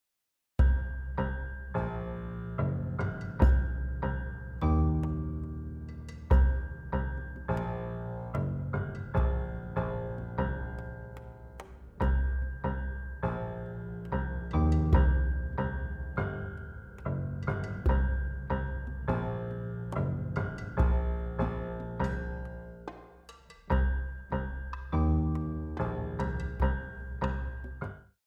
Soprano Sax, Bass, Piano, Percussion